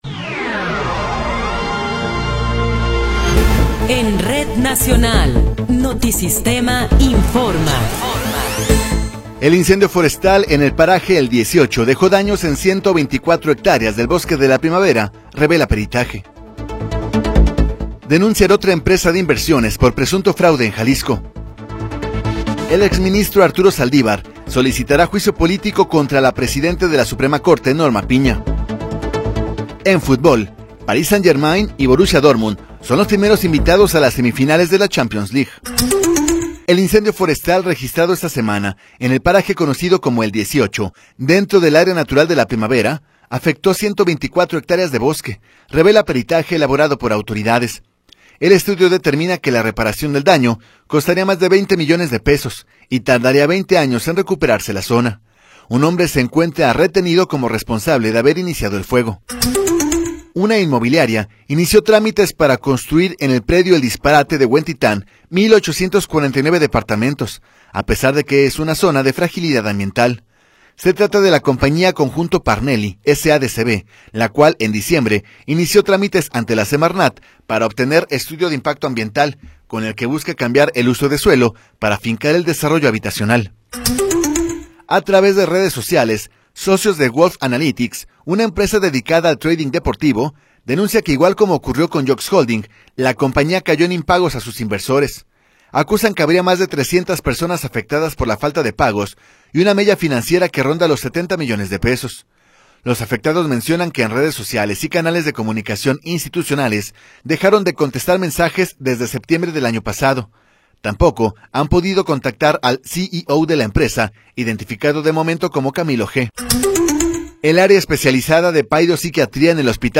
Noticiero 20 hrs. – 16 de Abril de 2024
Resumen informativo Notisistema, la mejor y más completa información cada hora en la hora.